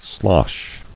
[slosh]